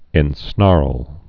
(ĕn-snärl)